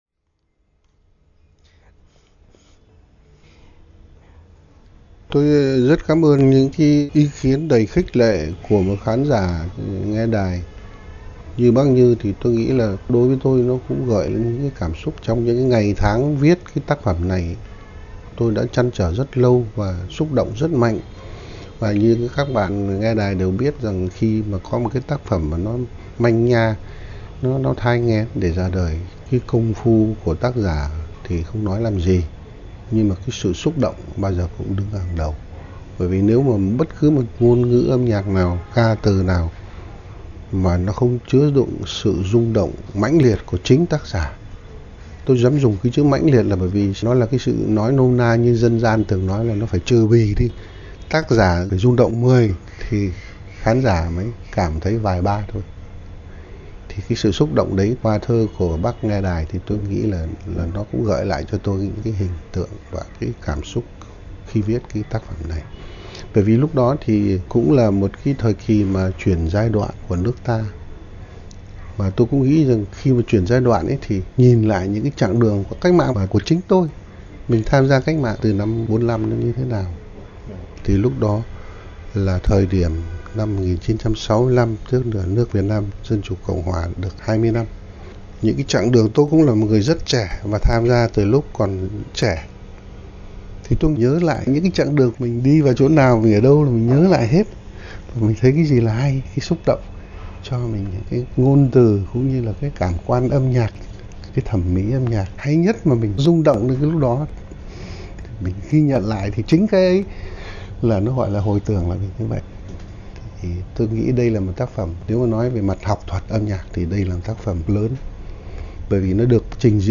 Băng tiếng phỏng vấn nhạc sĩ Hoàng Vân
Vào những năm cuối đời, Đài phát thanh tiếng nói Việt nam có tổ chức nhiều buổi trò chuyện với nhạc sĩ Hoàng Vân.
Phỏng vấn